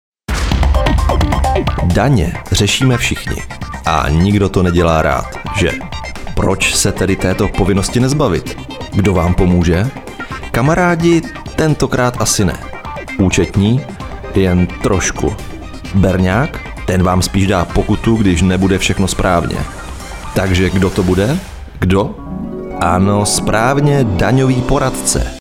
Umím: Voiceover
Mužský voiceover do Vašich videí